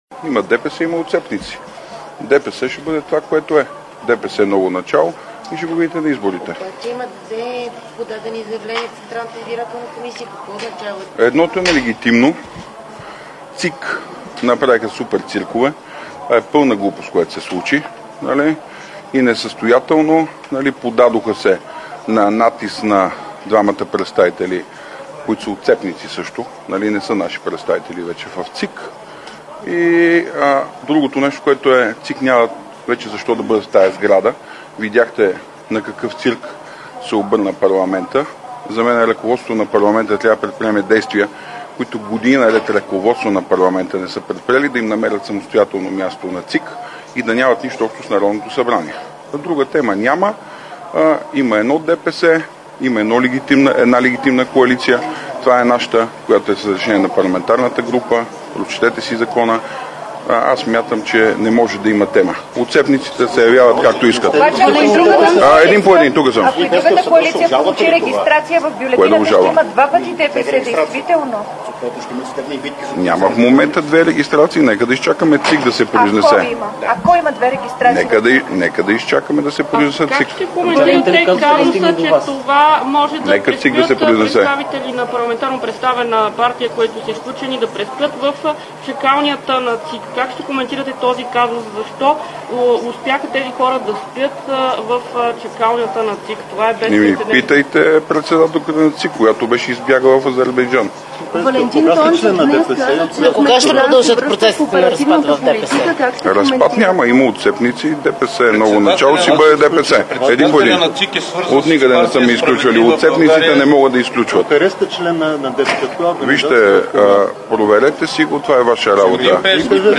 - директно от мястото на събитието (Народното събрание)
Директно от мястото на събитието